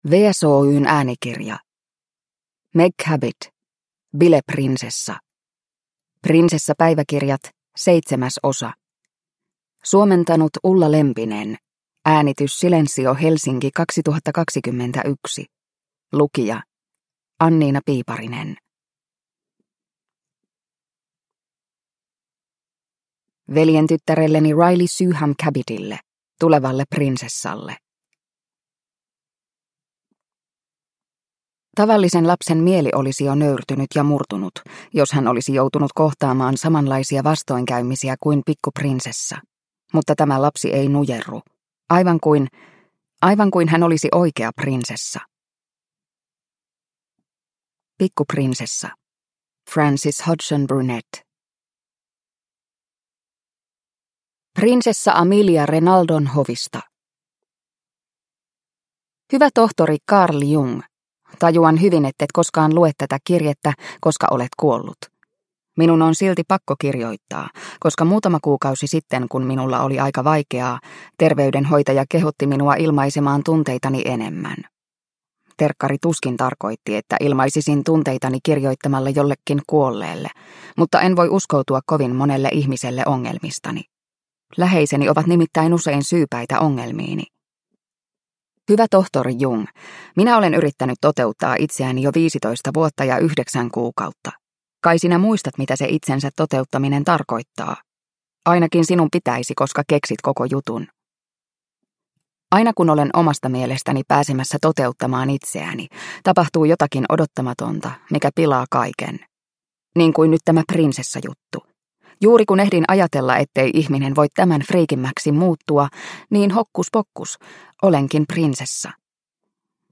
Bileprinsessa – Ljudbok – Laddas ner